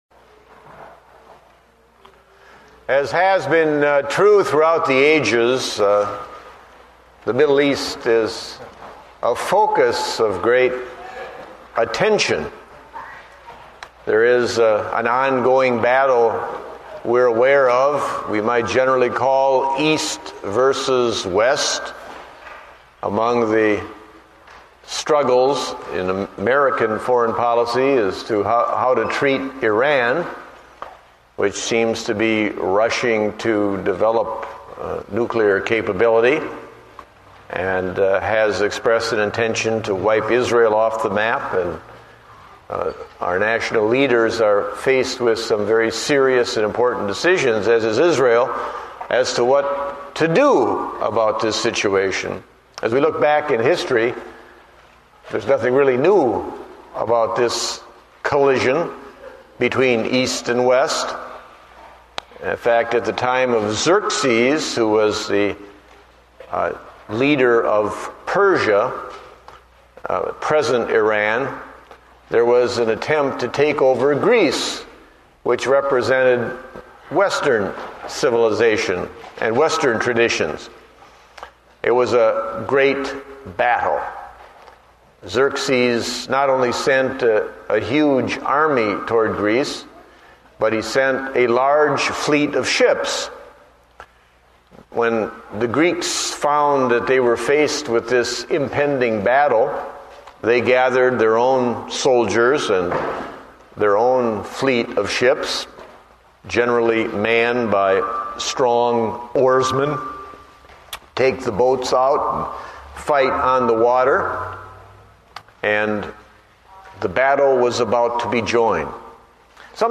Date: May 31, 2009 (Morning Service)